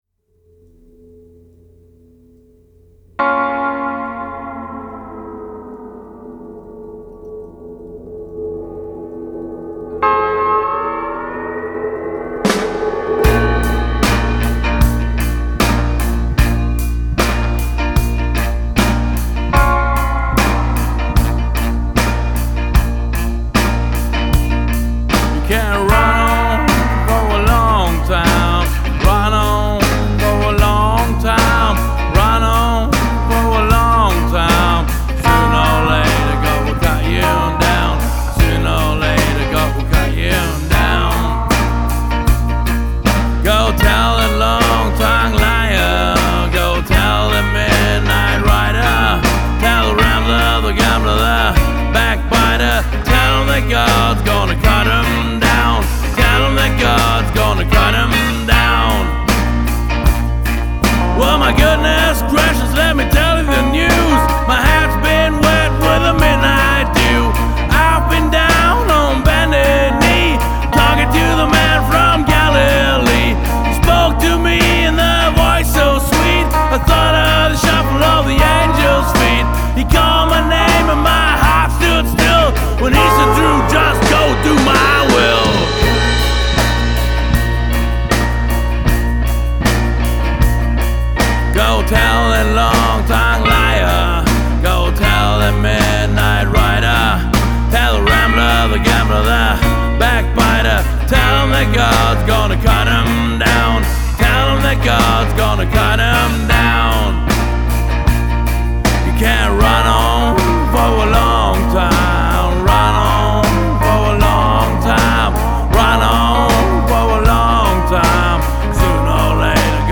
Rockabilly & Psychobilly